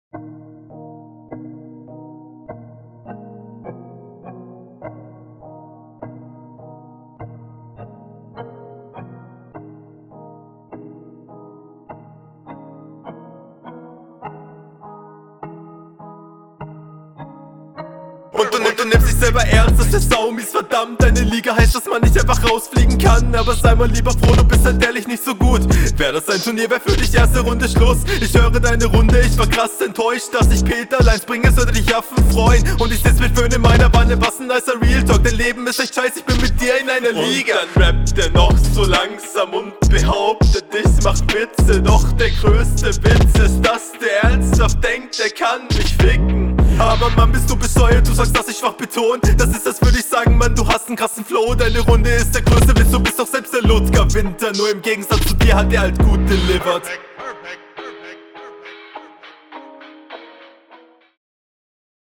Finde ich raptechnisch auf jeden Fall deutlich souveräner und du kommst auch sehr mächtig auf …
puhh die ersten vier zeilen sind'n sehr guter einstieg. schön gekontert und zurückgeschossen. cooler stimmeinsatz …